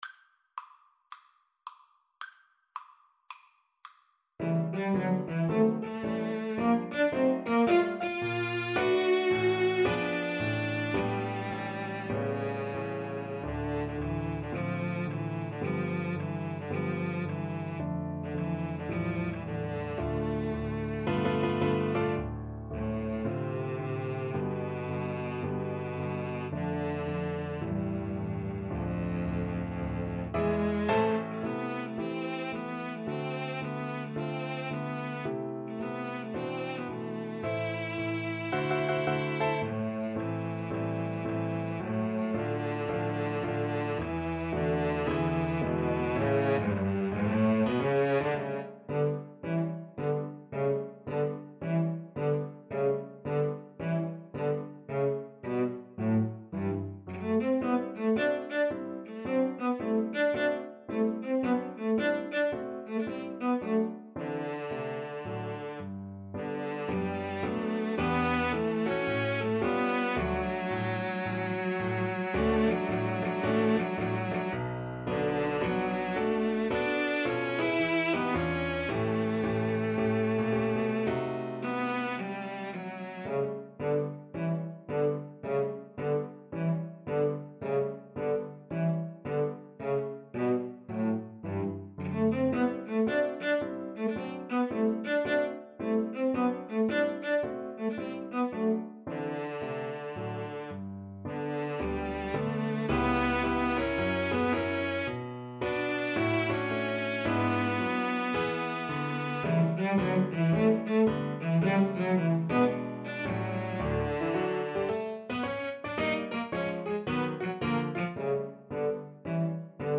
Moderato =110 swung